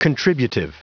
Prononciation audio / Fichier audio de CONTRIBUTIVE en anglais
Prononciation du mot contributive en anglais (fichier audio)